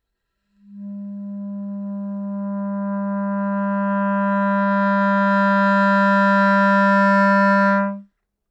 Every semitone is recorded as a crescendo note in an acousticely dry environment using top-notch mikrophones.
These are crescendo notes and get loud at the end!
Original: Reference recording
This is an original reference recording of a clarinet crescendo note. Focus on the tonal change of the harmonic structure as the clarinet is played louder, since this is the thing we try to reproduce here: